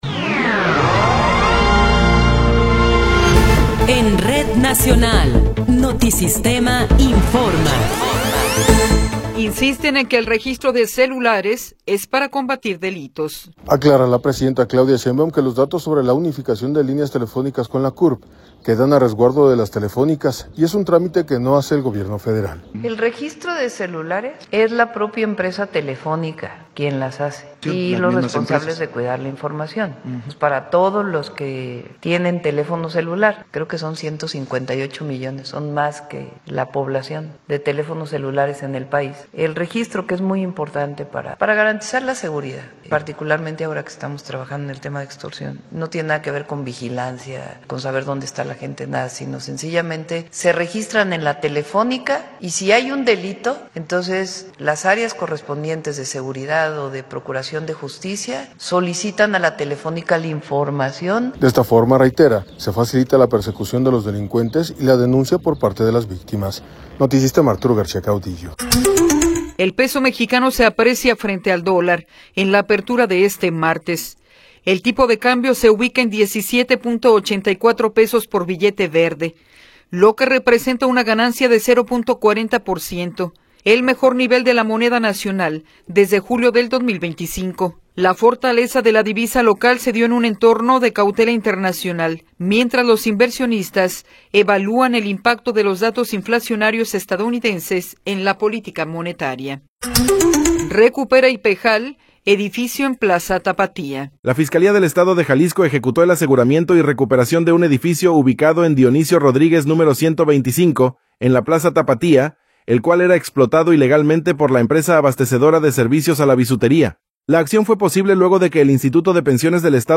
Noticiero 11 hrs. – 13 de Enero de 2026